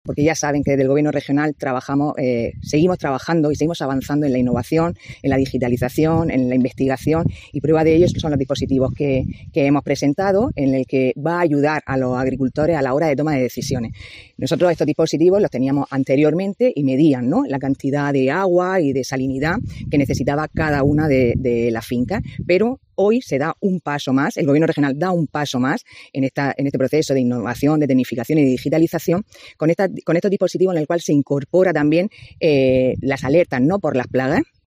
Sara Rubira, consejera de Agricultura